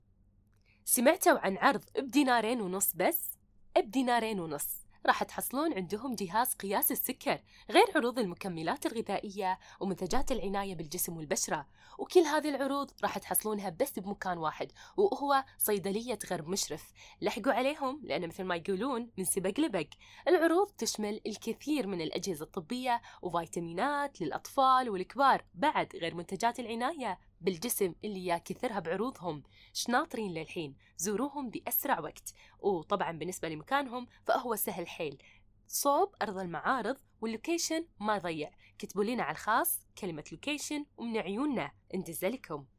اعلان صوتي لترويج لعروض صيدلية غرب مشرف في الكويت على الانستغرام ريلز بأسلوب عفوي دون تكلف ، لترويج للعروض